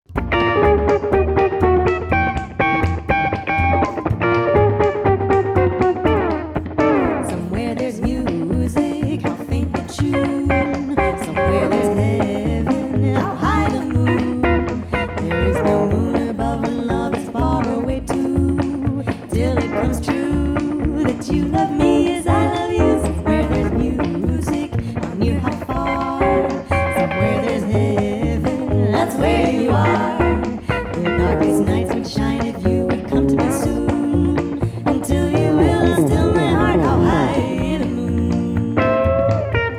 chant
piano
contrebasse.